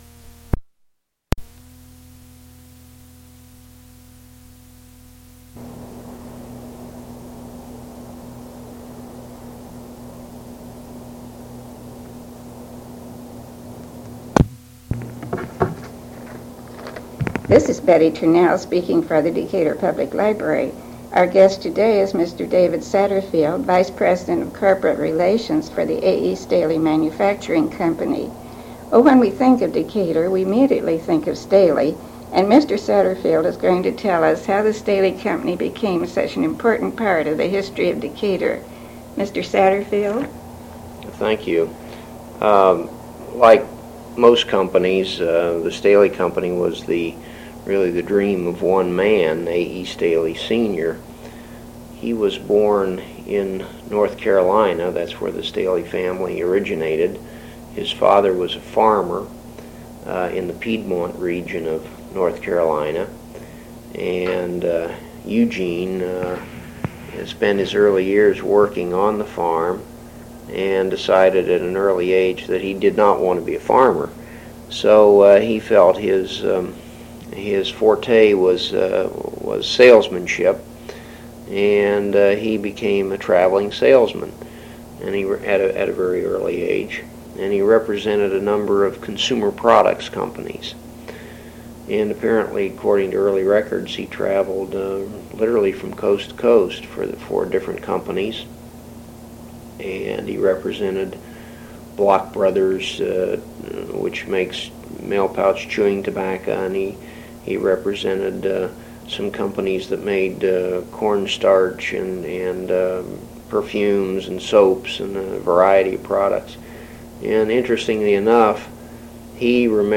interview , January 1983
oral history